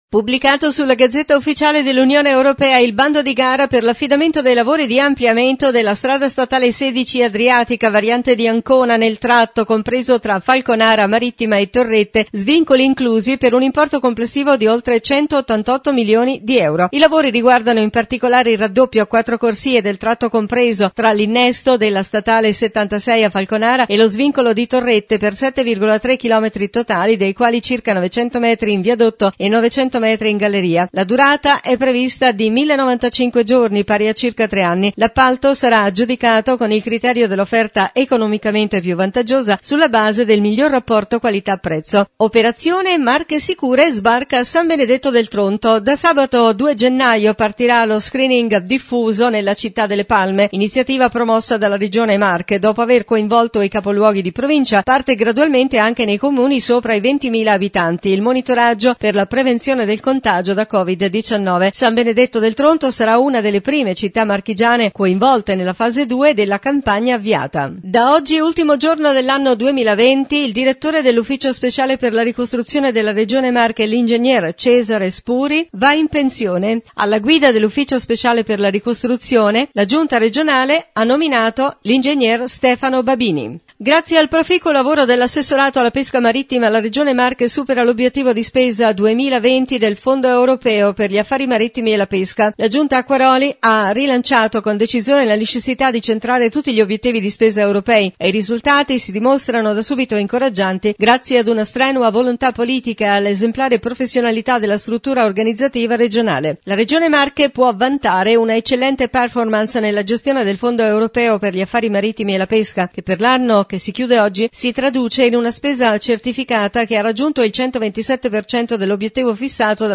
Francesco Baldelli – Assessore regionale alle Infrastrutture